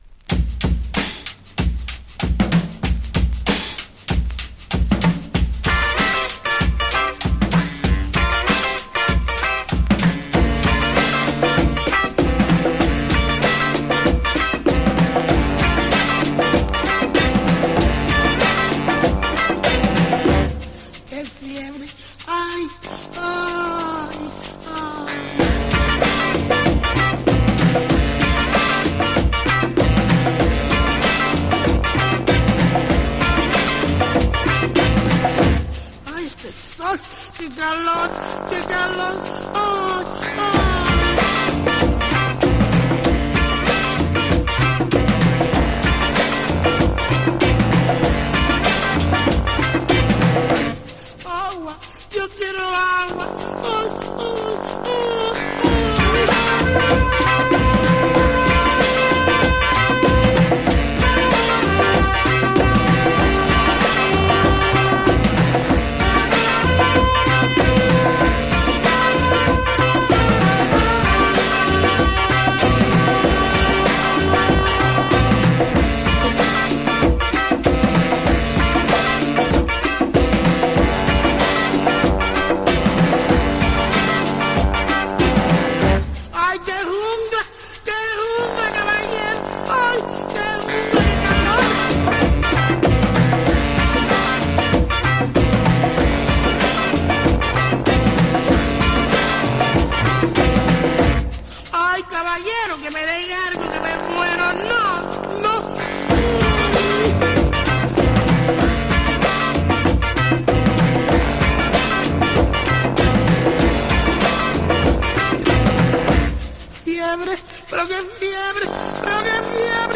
Funk / Rare Groove spain
ベルギーのラテン・ファンク・グループ
エロ＆鬼キラーブレイクの